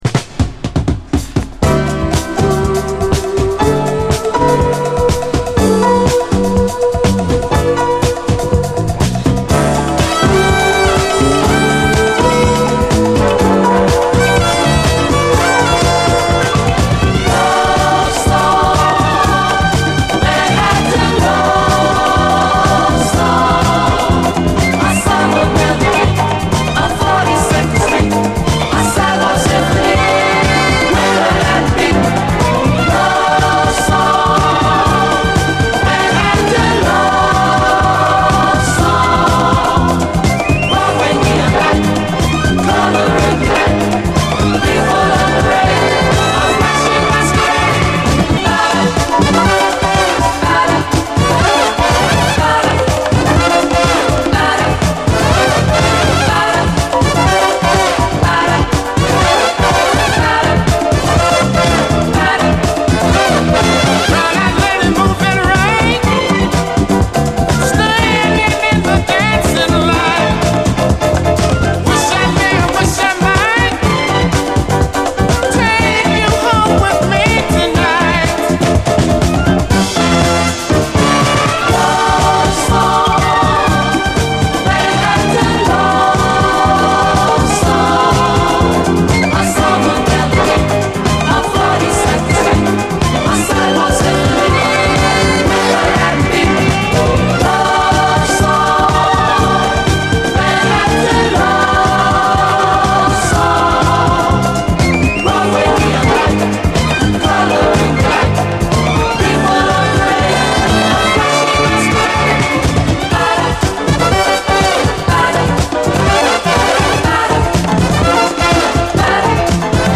A面アタマの無音部溝にジリッとノイズあり/
変化に富んだ目まぐるしい展開で魅せます！